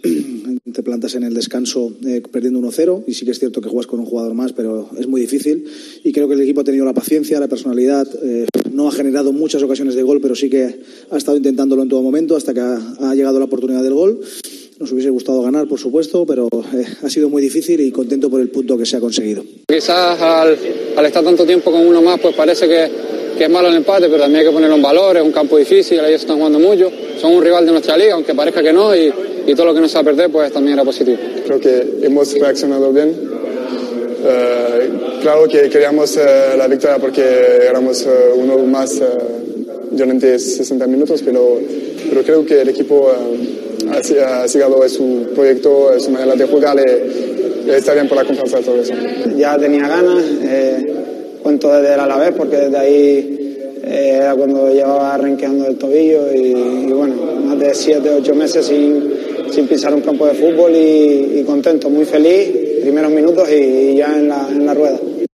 Declaraciones post partido
AUDIO: Declaraciones de García Pimienta, Alex Suárez, Loiodice y Fabio tras el encuentro